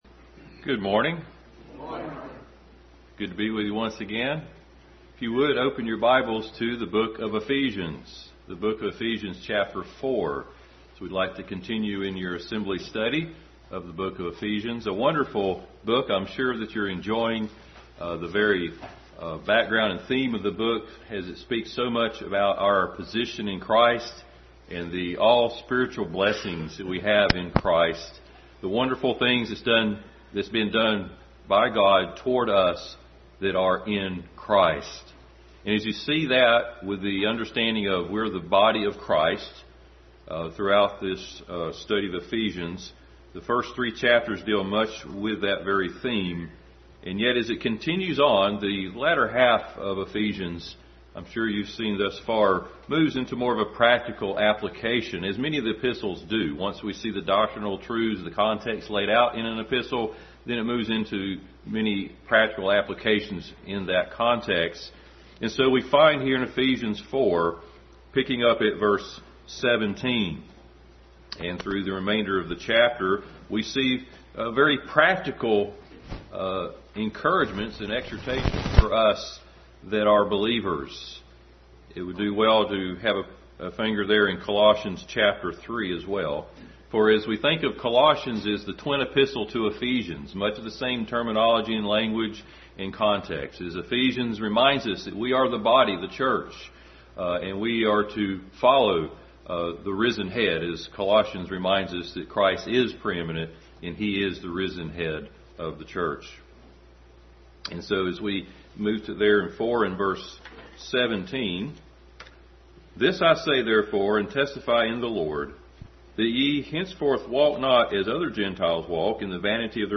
Adult Sunday School continued study in Ephesians.